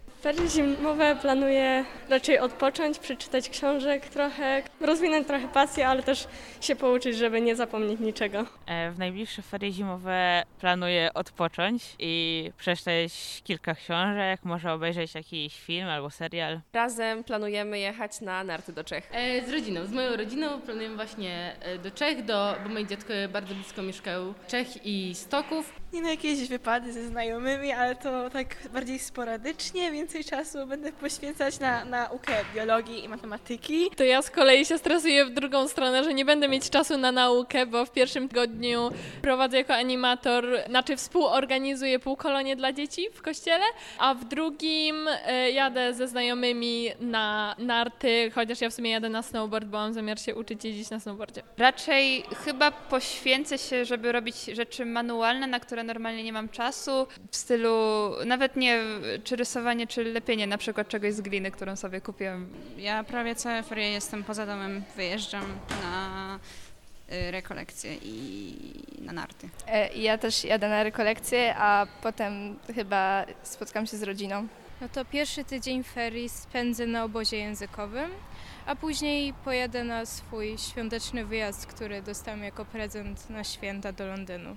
O plany na najbliższe dni zapytaliśmy wrocławian.